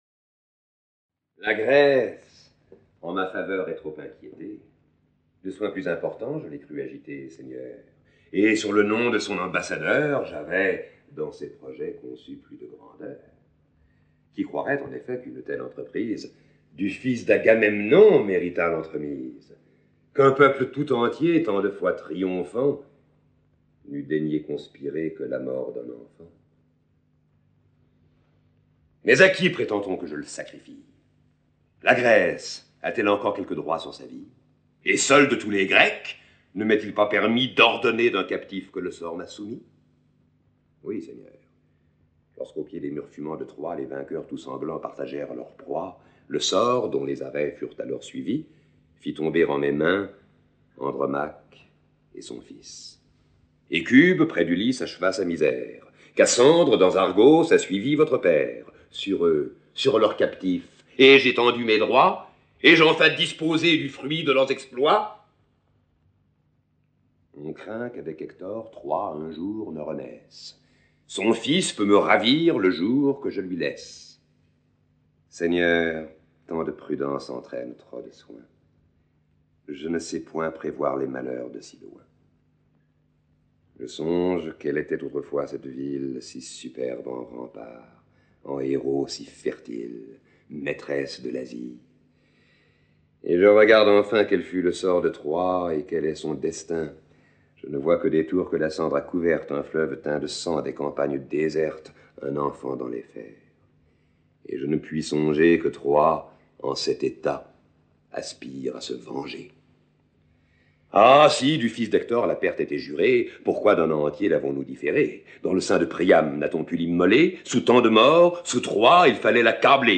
Ces extraits sonores sont issus de l'adaptation théâtrale de Don Juan dans les années 50 au TNP (Théâtre National Populaire), sous la direction de Jean Vilar, avec Jean Vilar, Daniel Sorano et Jean-Paul Moulinot. 6 scènes sont içi représentées : Acte I scènes 2, 3 et 4 ; Acte III scènes 4, 5 et 6.